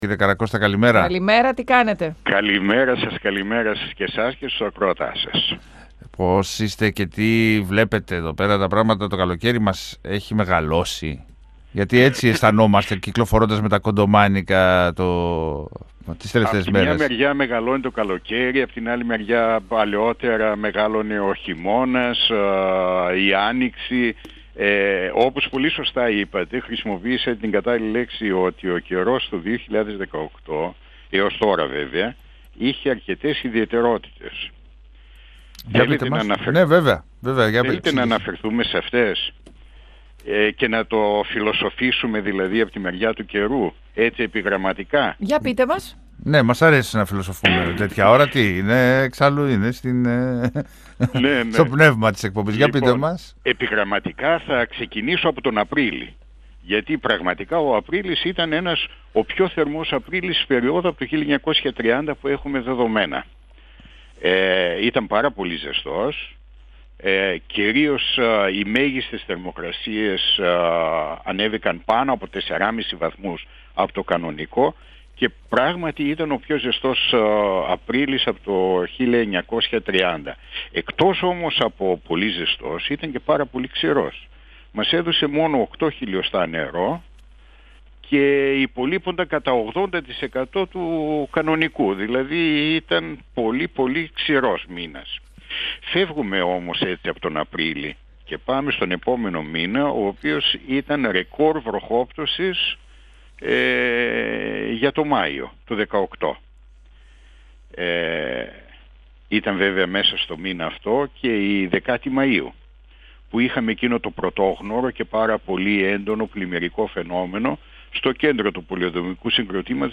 Αναφερόμενος στις ραγδαίες βροχές που κρατούν λίγο αλλά είναι πολύ έντονες, είπε ότι αυτό οφείλεται στη σταδιακή αύξηση της θερμοκρασίας. 102FM Συνεντεύξεις ΕΡΤ3